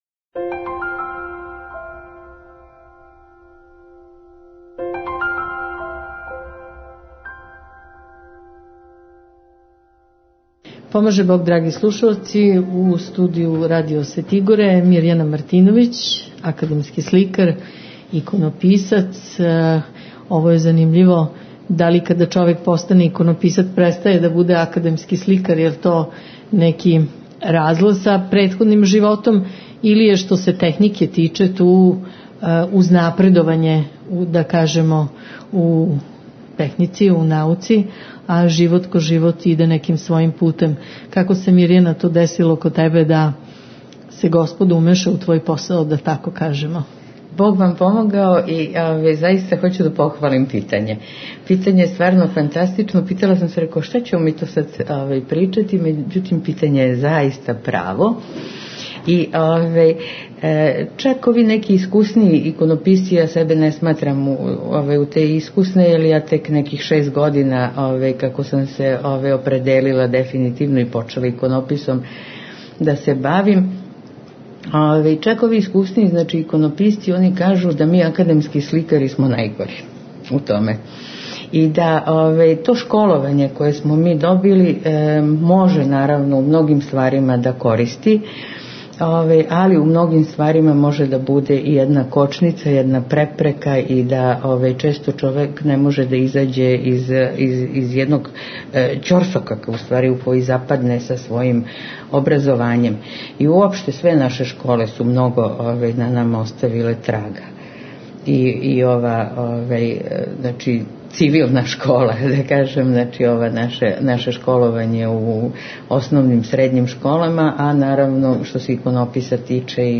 Академија је одржана у препуној великој дворани Коларчеве задужбине у Београду. На академији су бесједили Његово Високопреосвештенство Архиепископ цетињски и Митрополит Црногорско приморски Г. Амфилохије и Његово Преосвештенство умировљени Eпископ захумско – херцеговачки Г. Атанасије (Јевтић.
У програму су учествовали глумци, хорови и фолклорне и пјевачке групе.